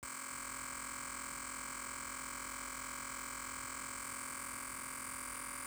onkyo-sound-with-input.wav